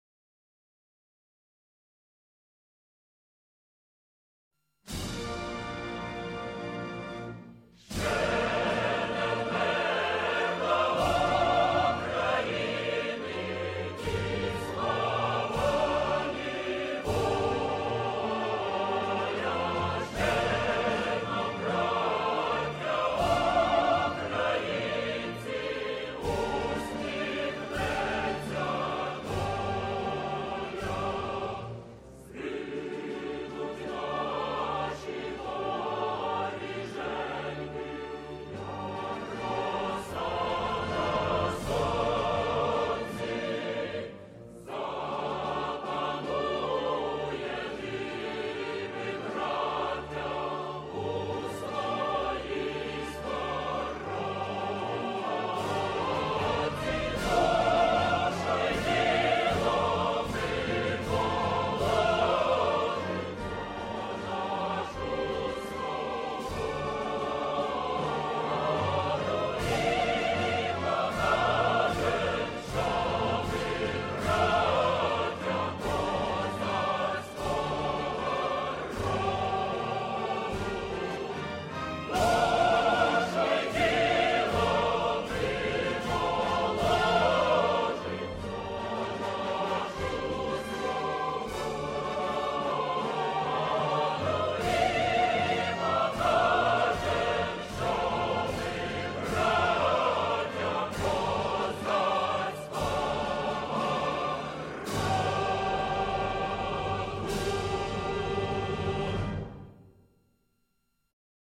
Гімн України з словами mp3